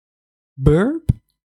Burp - vocal
Category 🗣 Voices
breathy female funny male mouth sound speaking speech sound effect free sound royalty free Voices